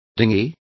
Complete with pronunciation of the translation of dinghy.